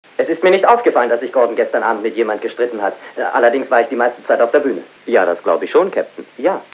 Sound file of German dubbing actor (106 Kb)